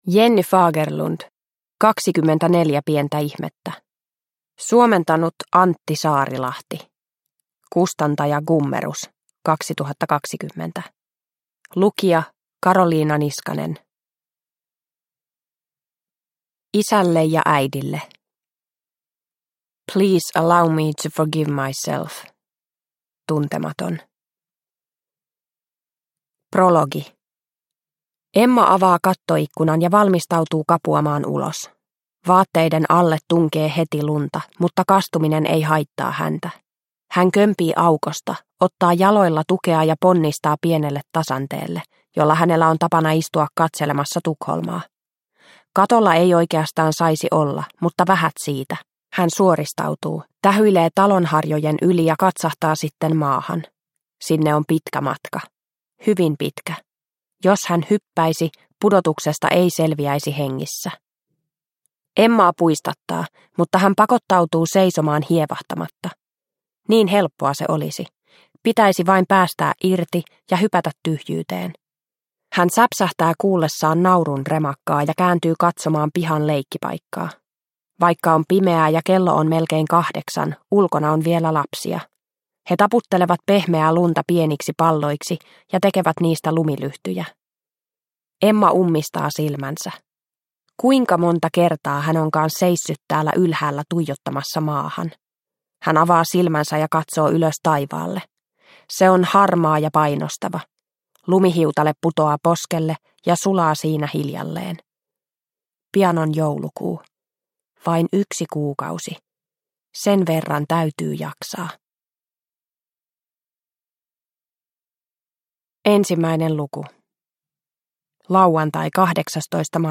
24 pientä ihmettä – Ljudbok – Laddas ner